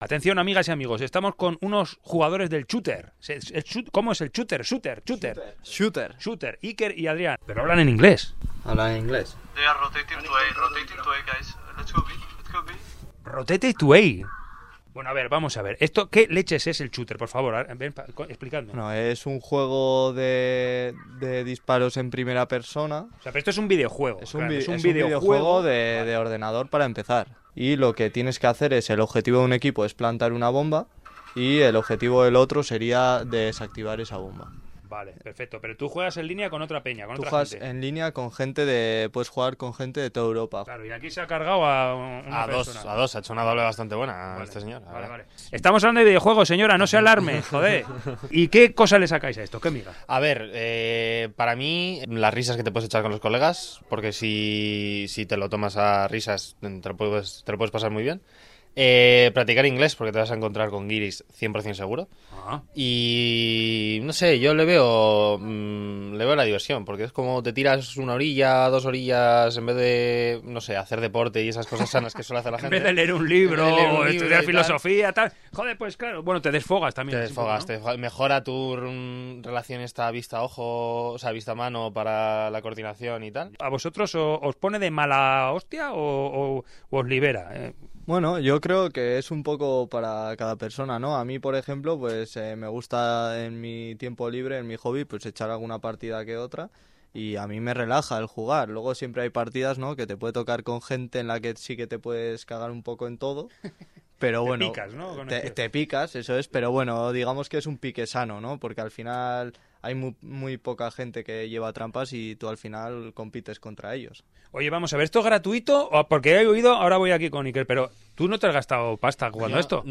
Audio: Un par de jóvenes nos muestra por qué engancha tanto este videojuego en línea. Millones de personas de todo el mundo pueden jugar entre sí haciendo campeonatos.